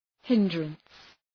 Προφορά
{‘hındrəns}